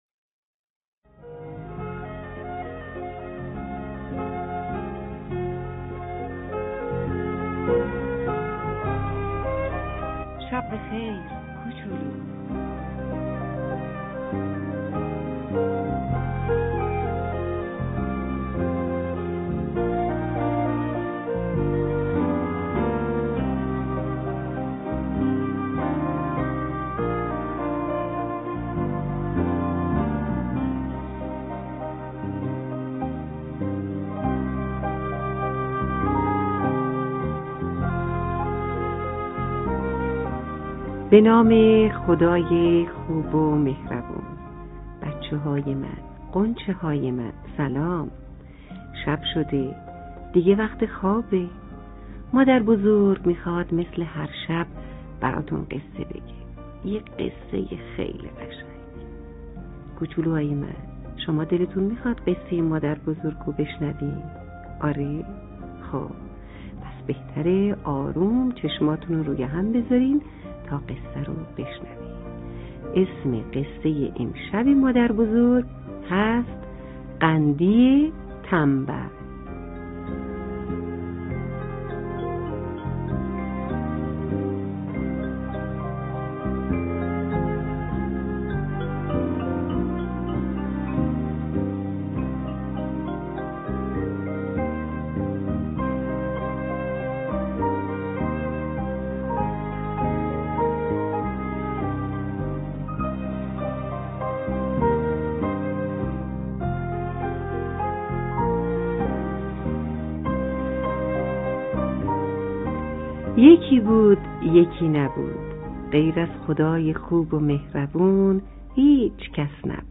قصه صوتی کودکانه؛ قندی تنبل